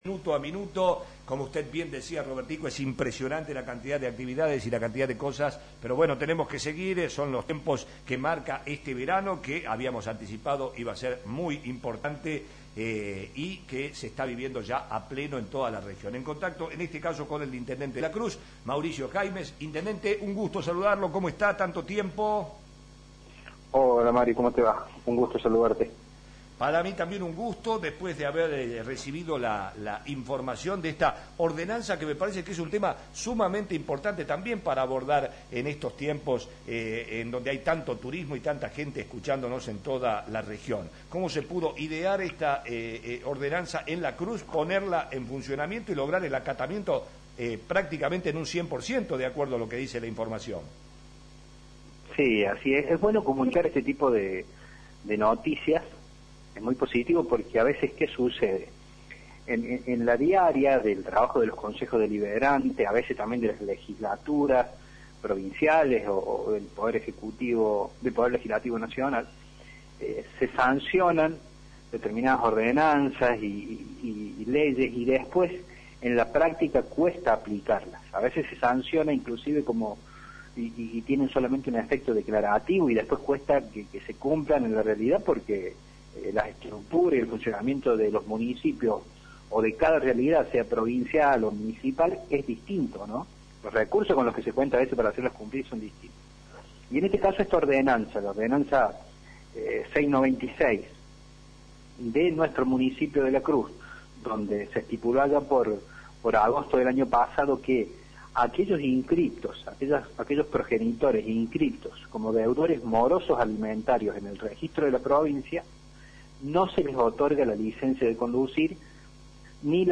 Sobre el tema dialogamos con el intendente Mauricio Jaimes quien detalló la ordenanza que se promulgó en agosto y logró que en la actualidad el 100 por ciento de los padres separados cumplan con los derechos de sus hijos.